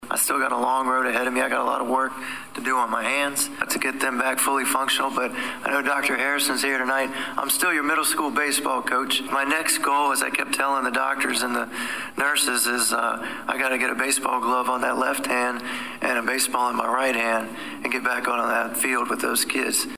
Just under three months after he was attacked and set on fire Danville City Councilman Lee Vogler attended his first city council meeting since the attack tonight.